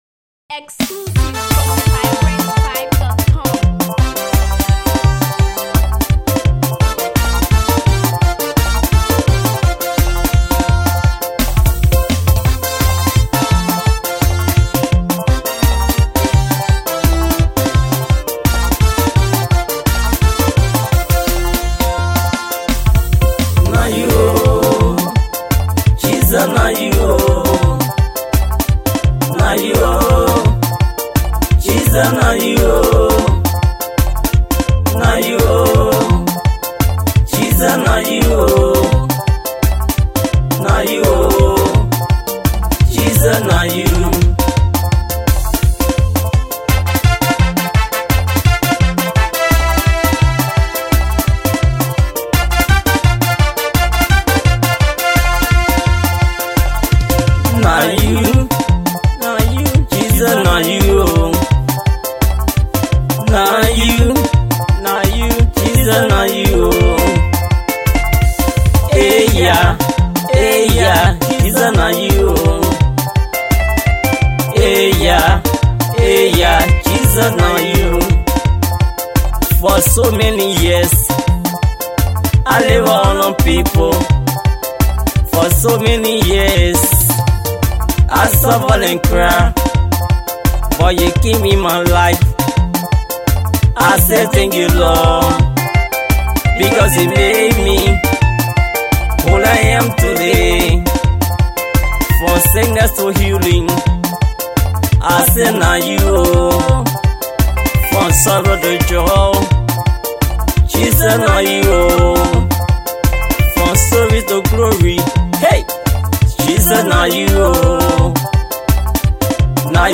A young energetic gospel Minister
Thanksgiving song